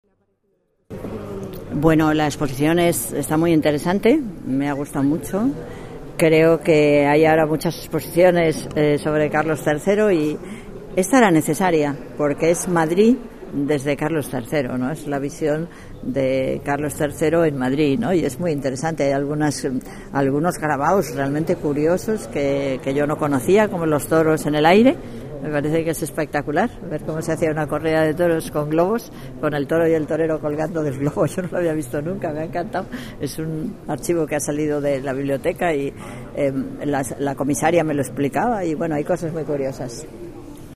Nueva ventana:Carmena habla de algunos detalles de la exposición